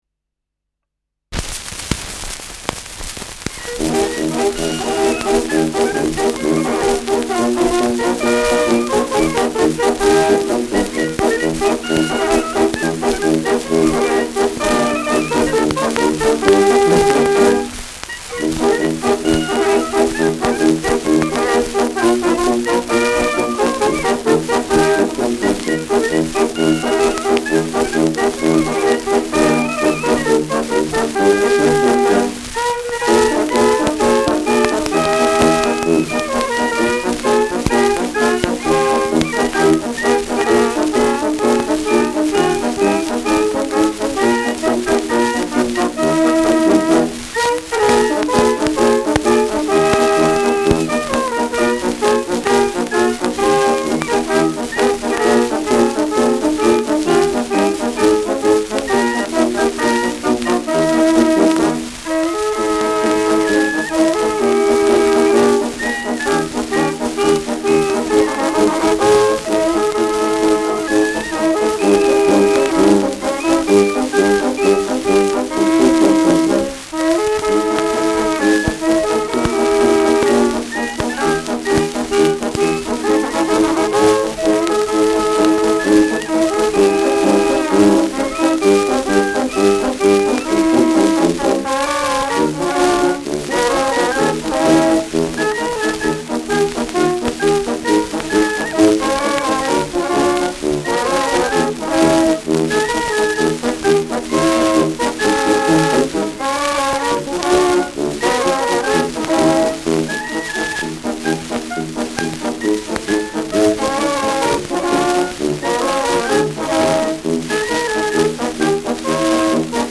Schellackplatte
Tonrille: Abrieb : Kratzer durchgängig
präsentes Rauschen : Knistern
[Nürnberg] (Aufnahmeort)